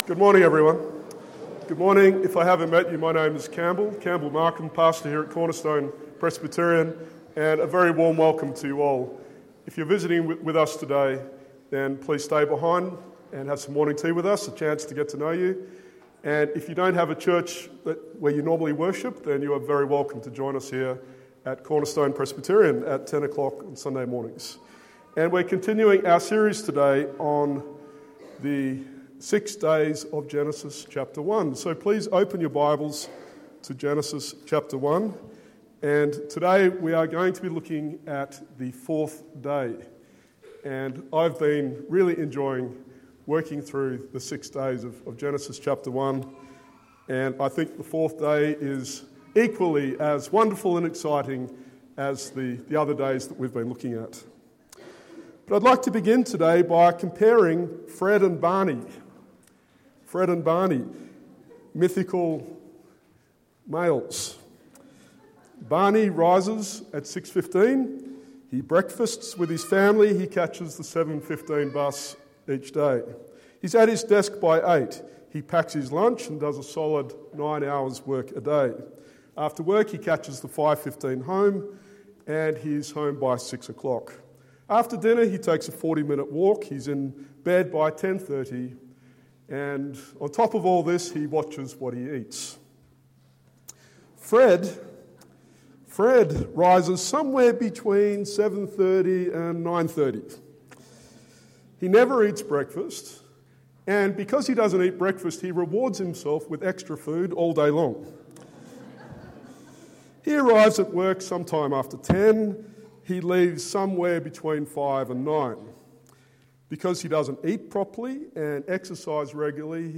Genesis 1:14-19 Sermon